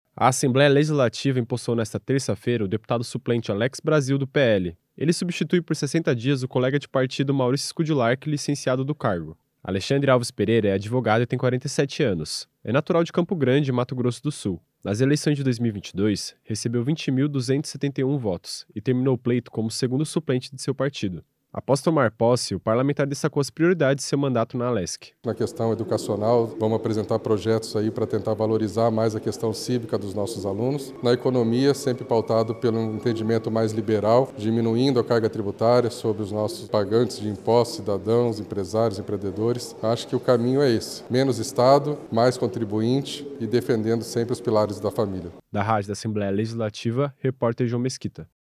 Entrevista com:
- Alex Brasil (PL), deputado suplente.